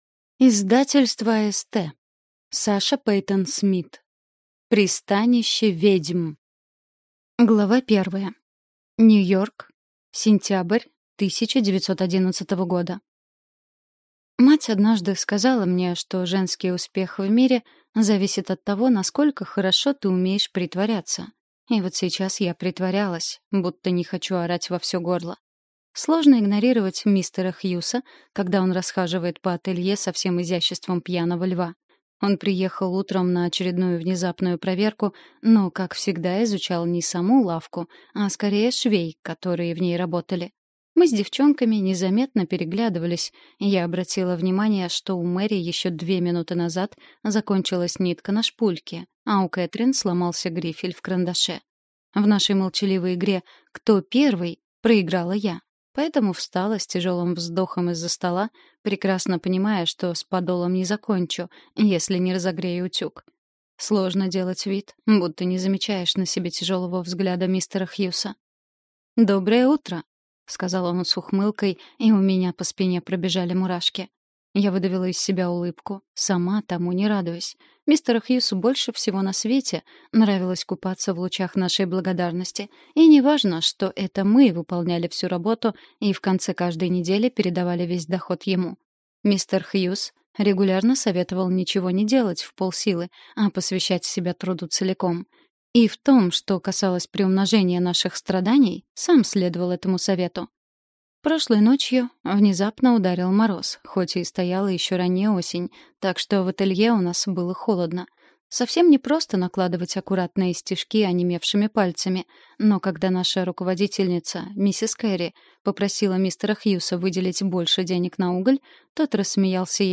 Аудиокнига Пристанище ведьм | Библиотека аудиокниг